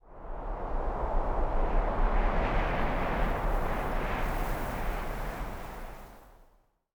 housewind14.ogg